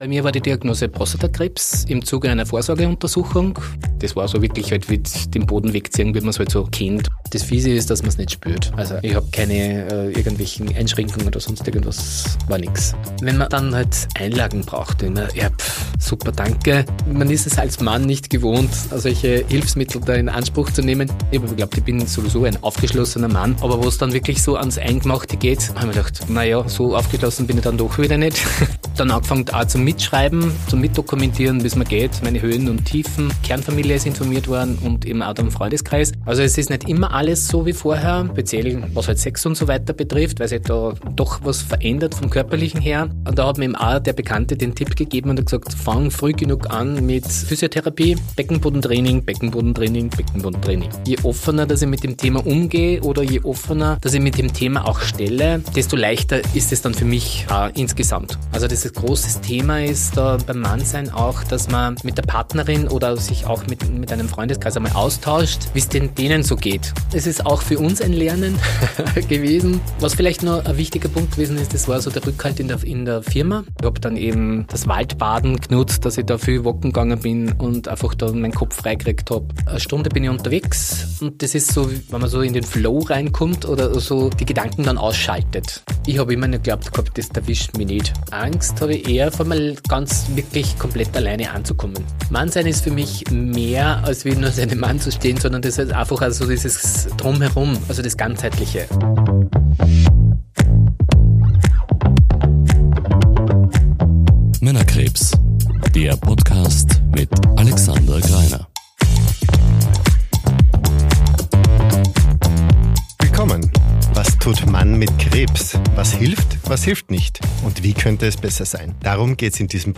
Gespräch) · Folge 22 ~ Männerkrebs – Was tut Mann mit Krebs?